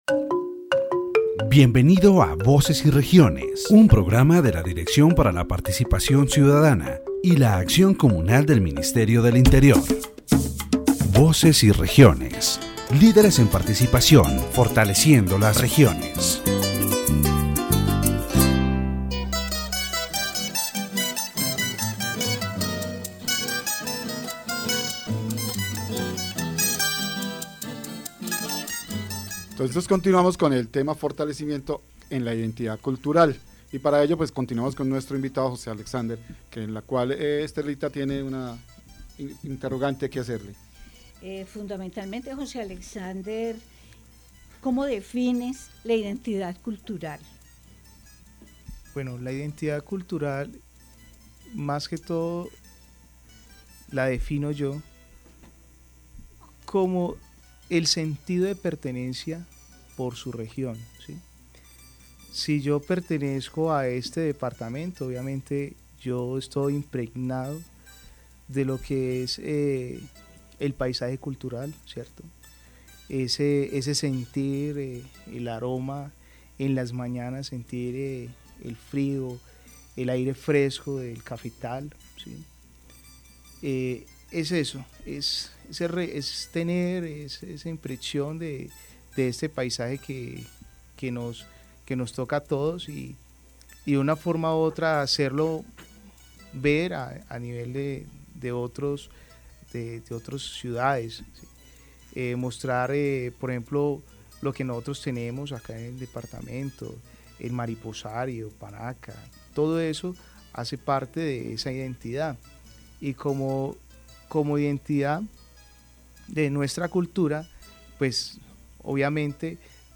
In this edition of Voces y Regiones, the concept of cultural identity and the elements that strengthen it in the department of Quindío are explored. The interviews highlight the value of Quindío's culture, emphasizing its natural wealth, diverse climate, and traditions that have played a key role in shaping its identity.